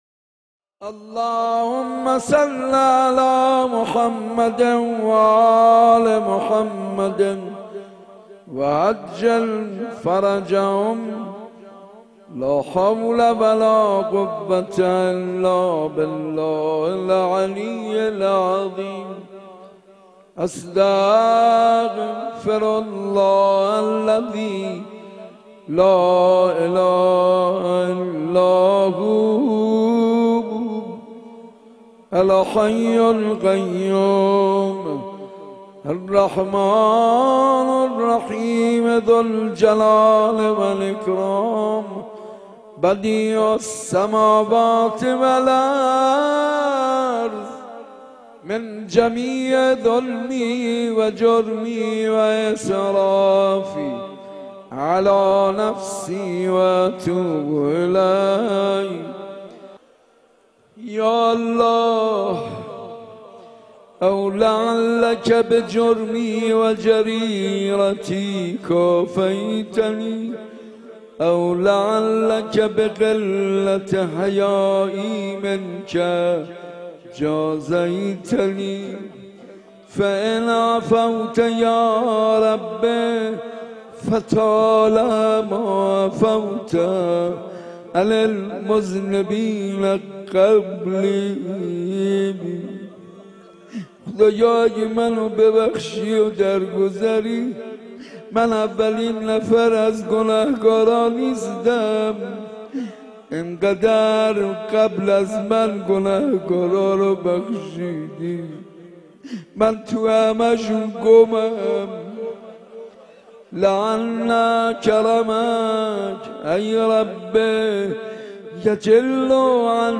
حاج منصور ارضی/شب بیستم رمضان94 /مسجد ارک
گلچین مراسم شب بیستم - حاج منصور ارضی